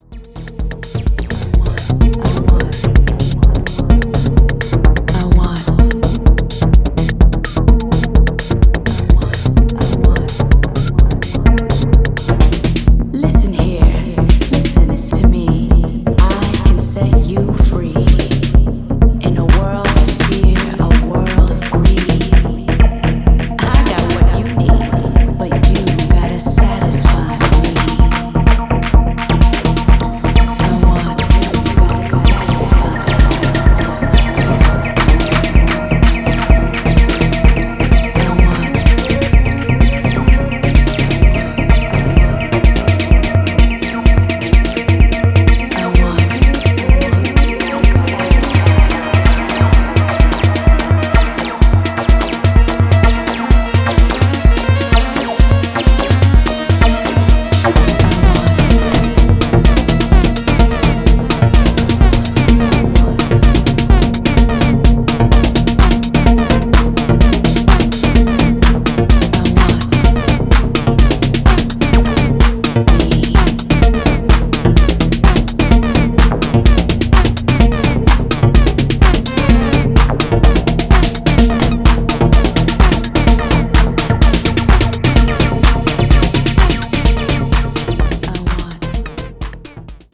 ジャンル(スタイル) HOUSE